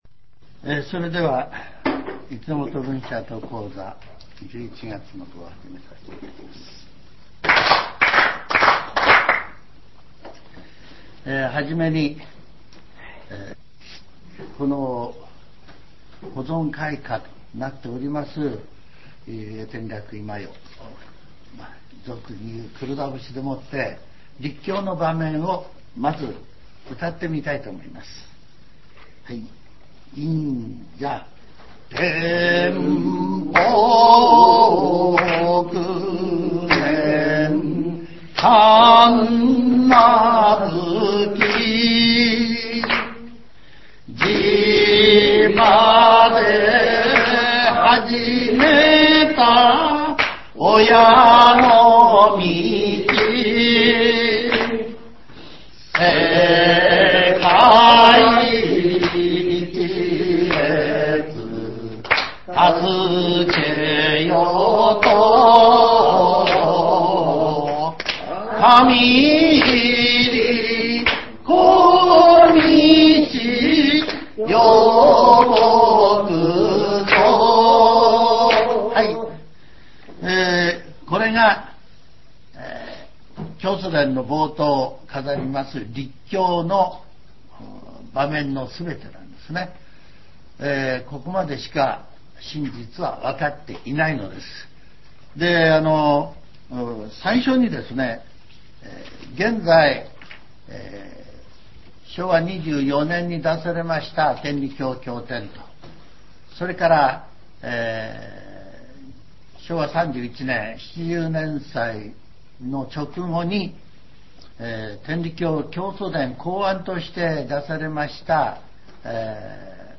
全70曲中15曲目 ジャンル: Speech